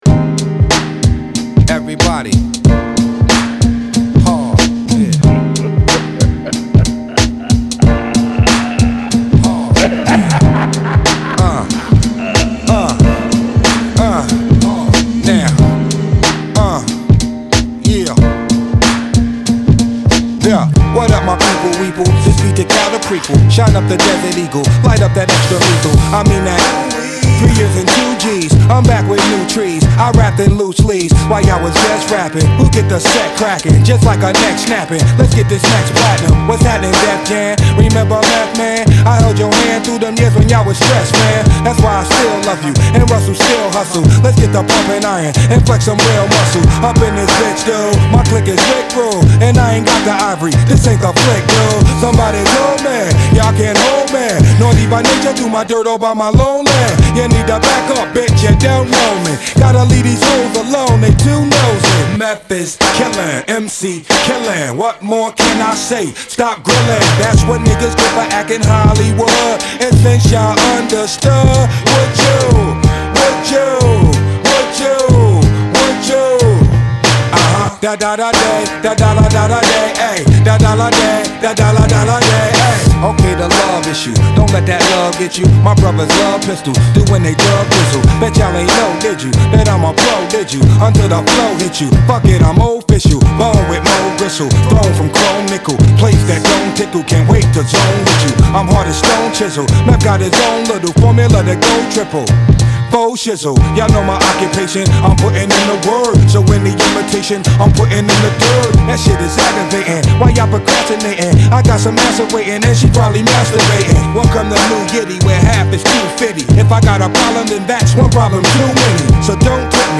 But then mashups came on to the scene
This mashup fixes the lyrical inadequacy with its rapping